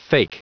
Prononciation du mot fake en anglais (fichier audio)
Prononciation du mot : fake